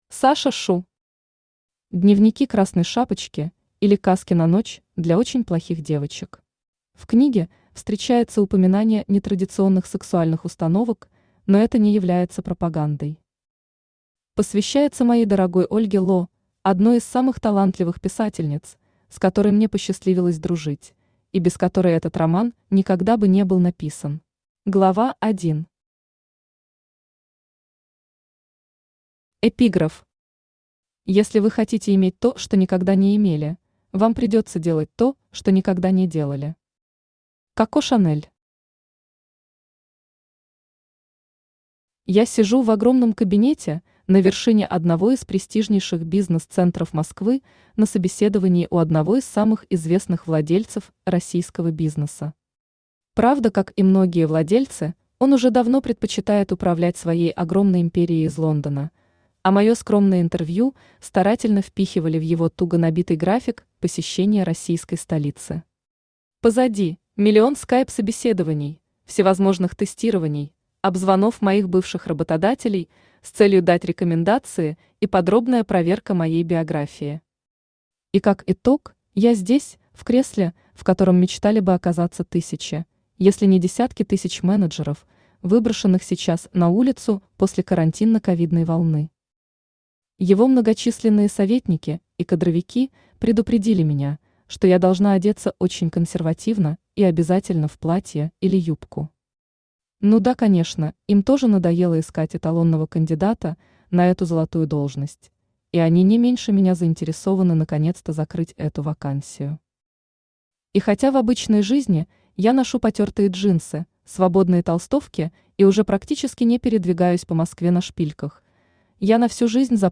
Aудиокнига Дневники Красной Шапочки, или Cказки на ночь для очень плохих девочек Автор Саша Шу Читает аудиокнигу Авточтец ЛитРес.